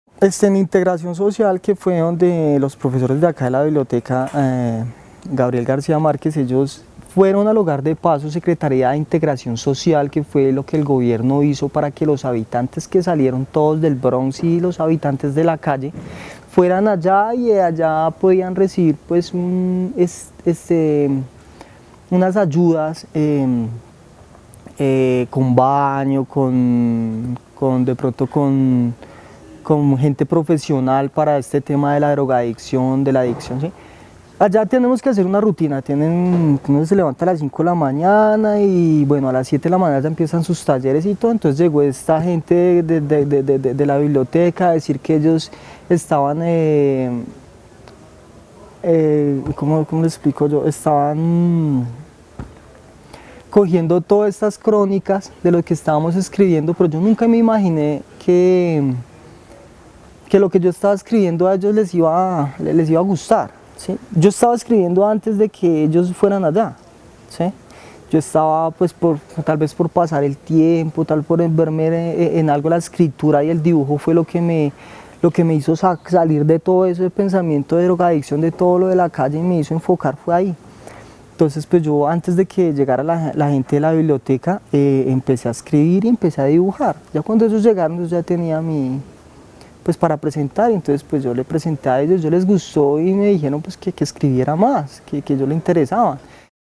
Testimonio de un habitante de calle de Bogotá sobre su experiencia de escritura de crónicas de ciudad. El testimonio fue grabado en el marco de los Clubes de Memoria y su participación en el programa Patrimonio y Memoria, de la línea de Espacios Creativos de BibloRed.